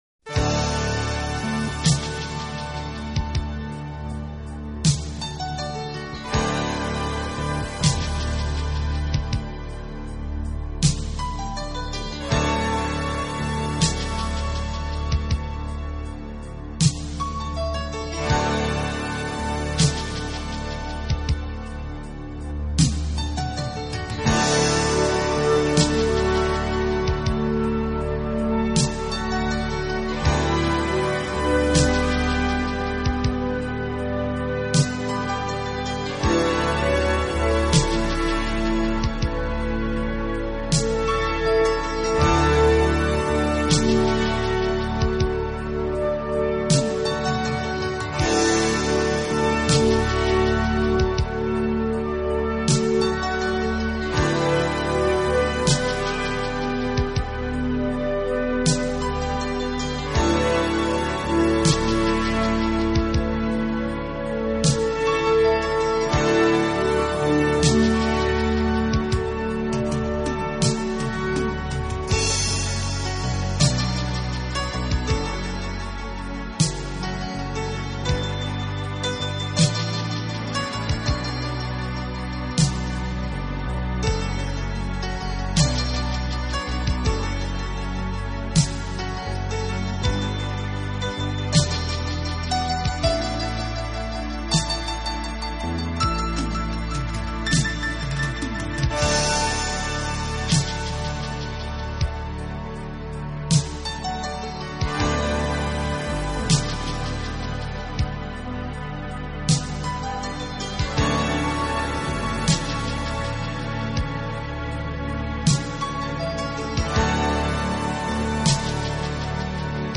【顶级轻音乐】
超炫的音质  旋律舒展而令人回味